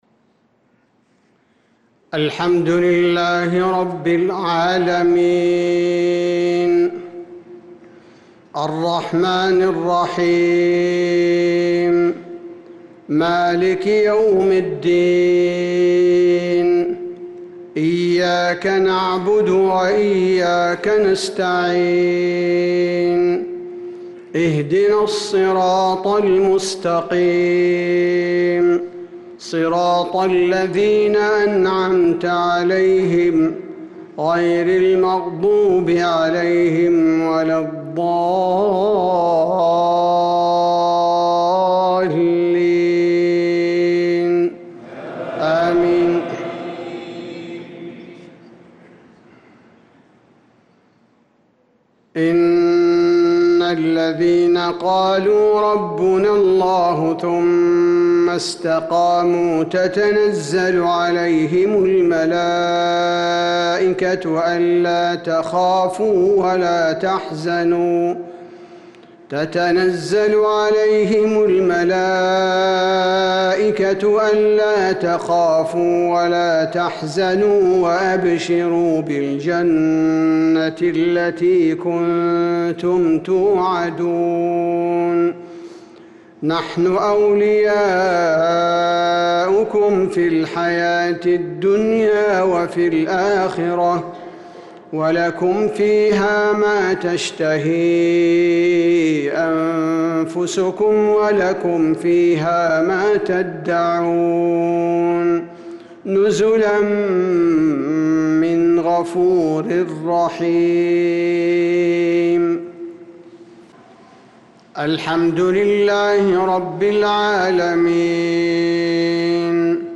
صلاة المغرب للقارئ عبدالباري الثبيتي 8 ذو القعدة 1445 هـ
تِلَاوَات الْحَرَمَيْن .